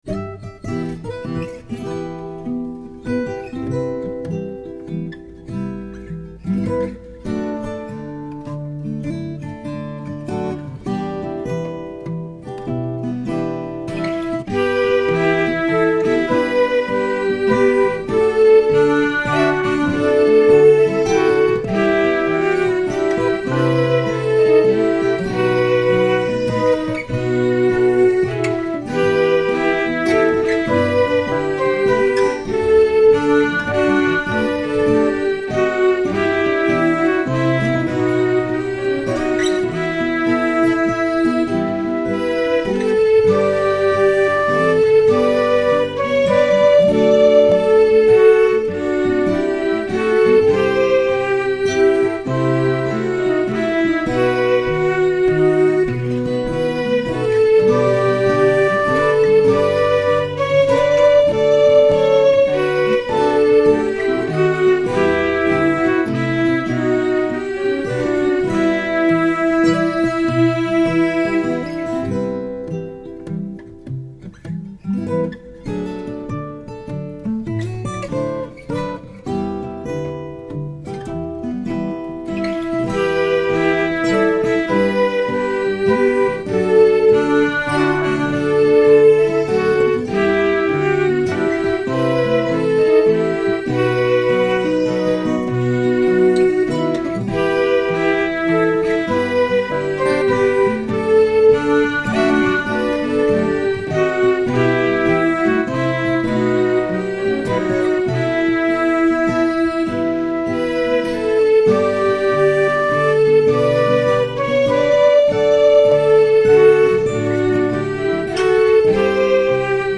as a counter melody